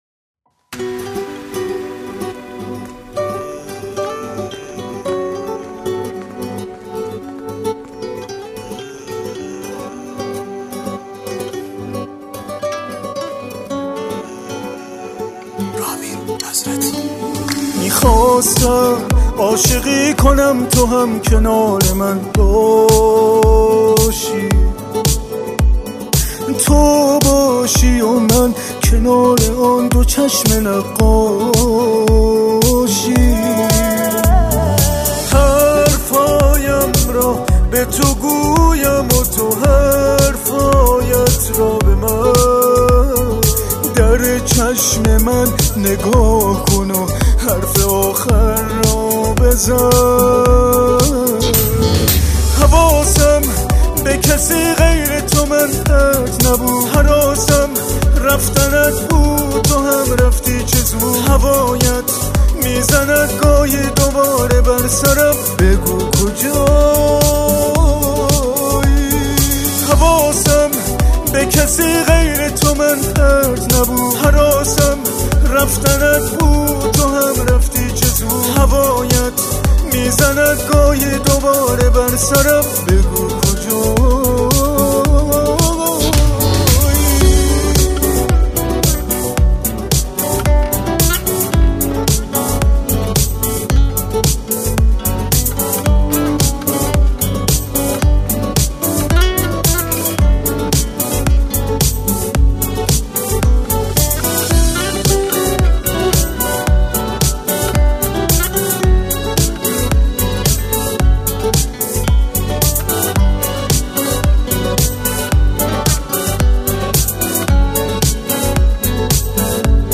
• سنتی ایرانی
دسته : سنتی ایرانی